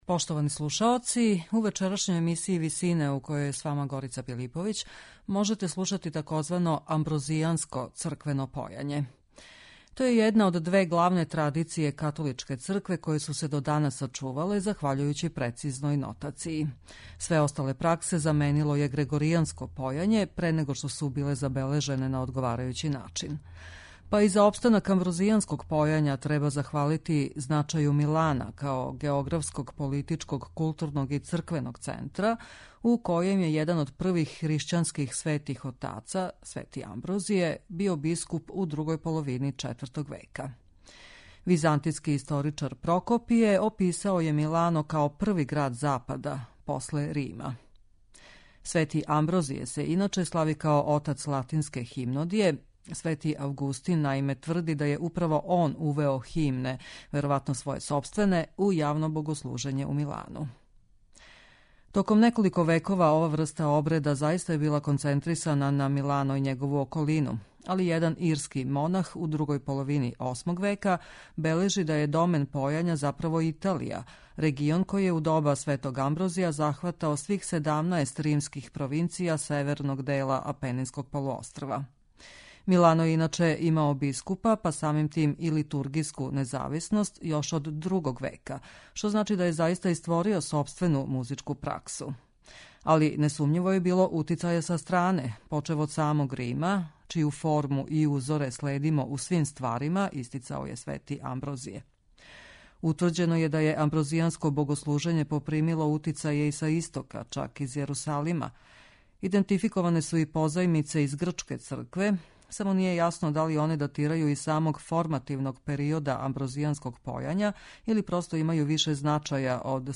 Амброзијанско певање